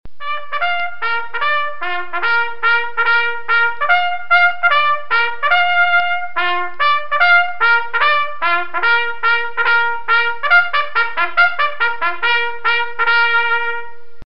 Звуки флага
Звук трубы при поднятии флага звучит в лагере музыкально